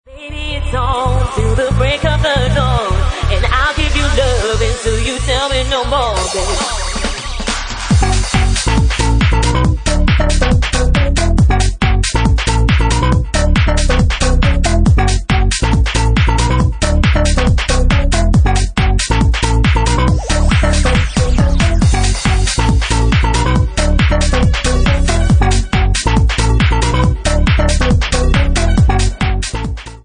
Bassline House at 185 bpm